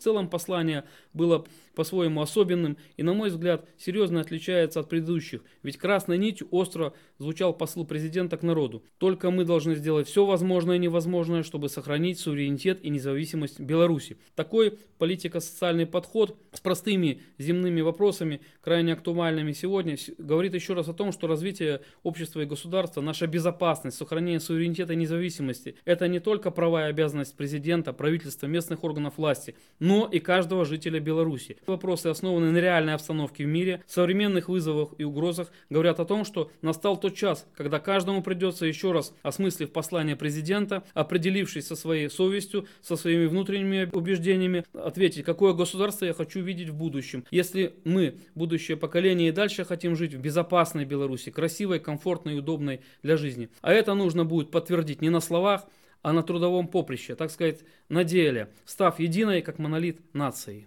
Направления, которые Глава государства обозначил на текущий год, — это укрепление нашего суверенитета и независимости. Эти идеи проходили красной нитью через весь доклад, на протяжении всего разговора, отметил депутат Палаты представителей Национального собрания Игорь Хлобукин.